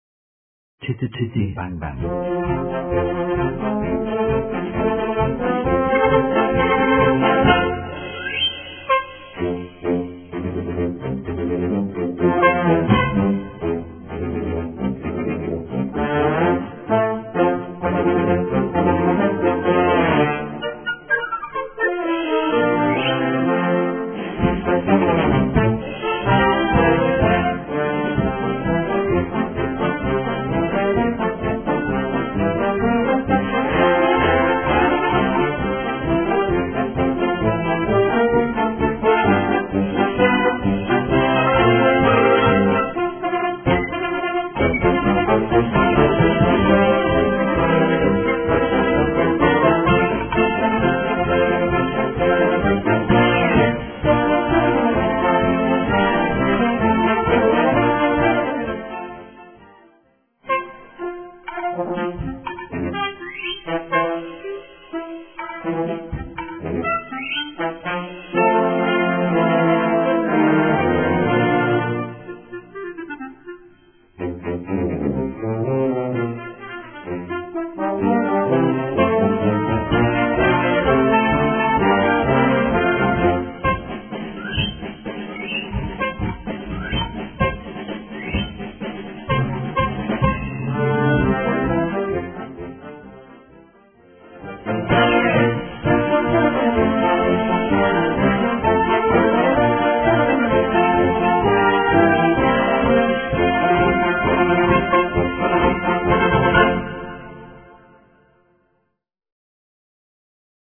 Ab 7-stimmiger Besetzung spielbar.
Gattung: Modernes Jugendwerk
Besetzung: Blasorchester